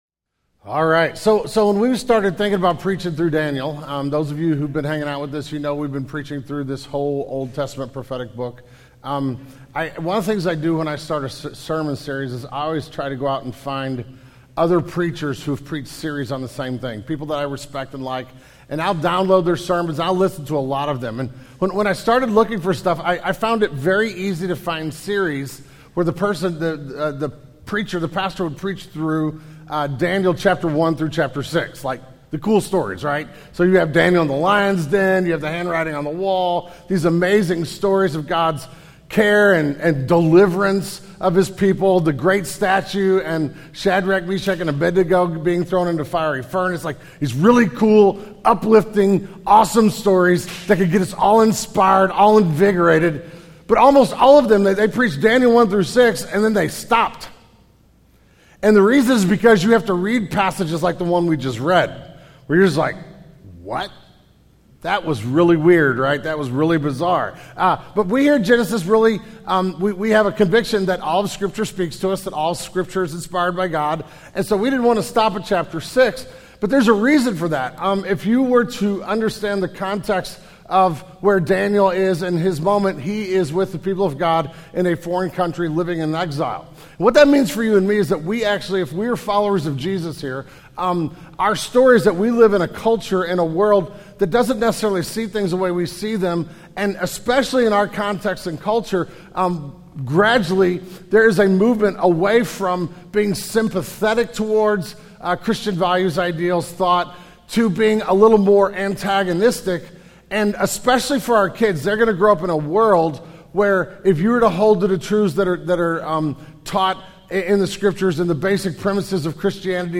We will take a look at the Biblical doctrine of the spiritual world and spiritual warfare in this sermon, and be reminded that the real battle happens on our knees.